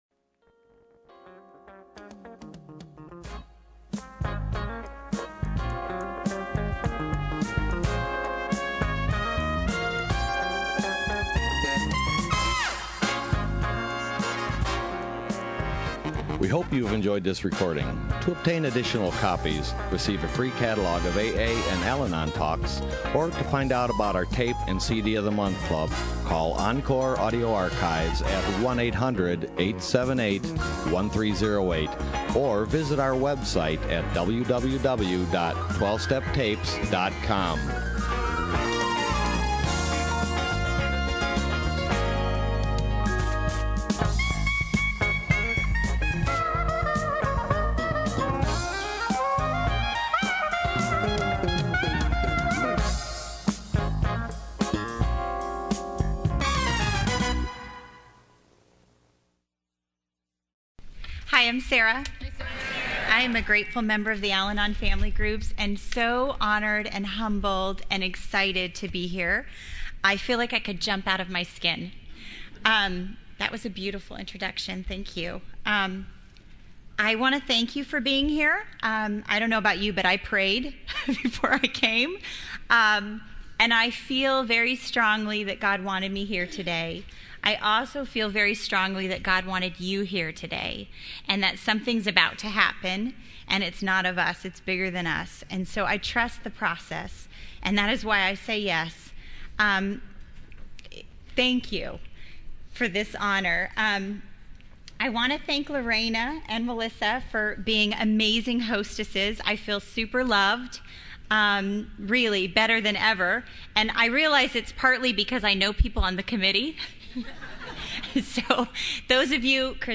Orange County AA Convention 2013
AFG LUNCHEON &#8211